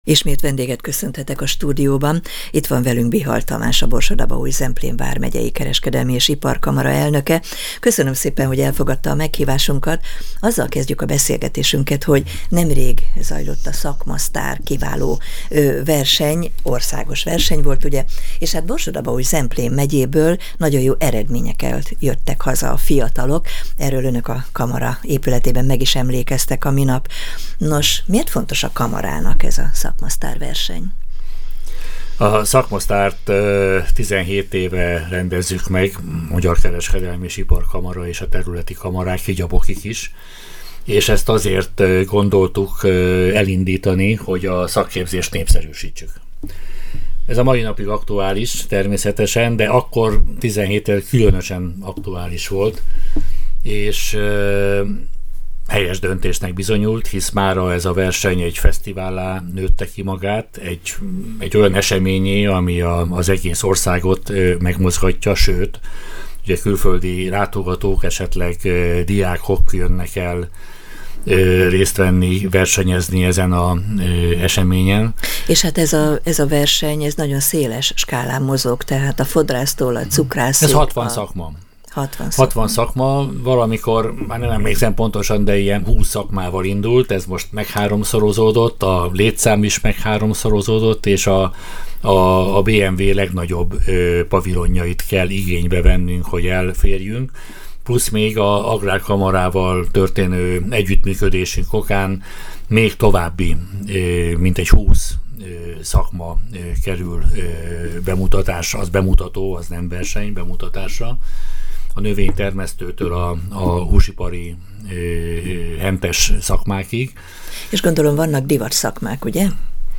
Ennek fontosságáról, a hiány szakmákról beszélgetettünk. A riporter